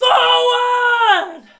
battle-cry-4.ogg